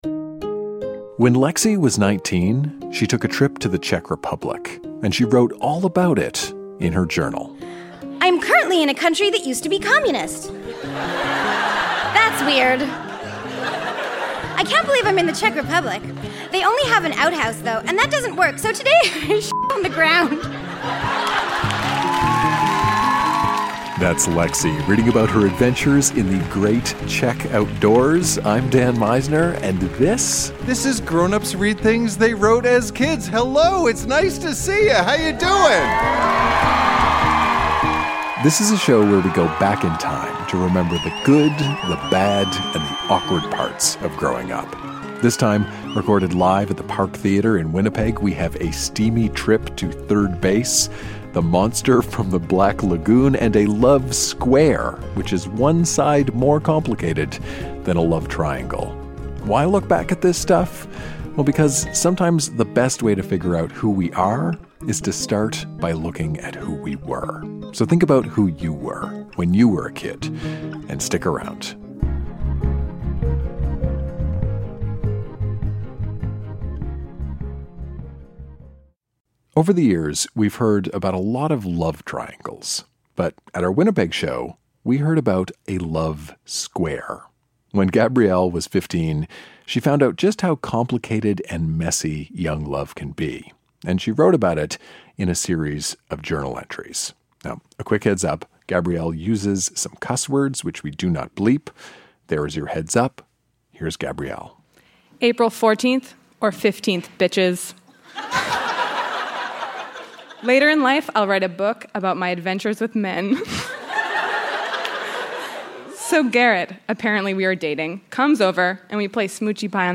Recorded live at the Park Theatre in Winnipeg, MB.